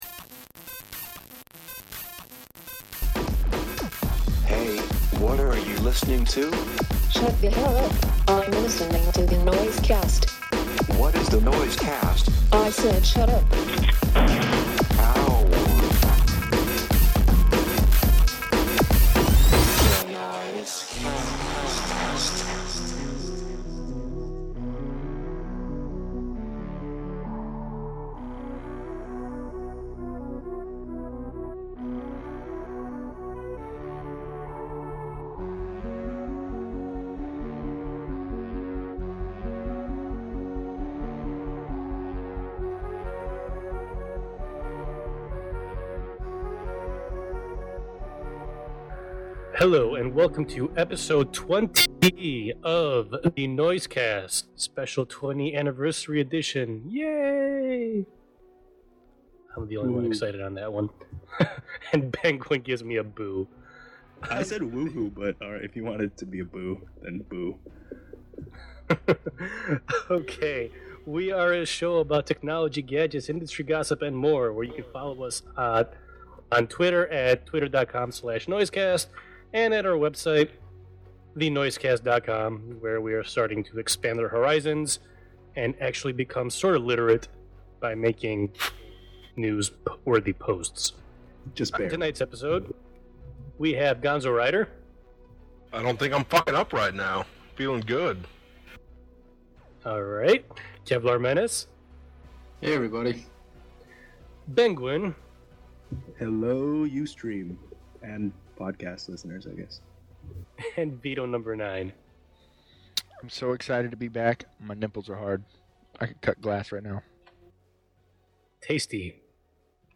This time I’m sorry to say we took a considerable step back in sound quality due to technical difficulties but we had a great time recording and chatting with people in the Ustream as we streamed it live.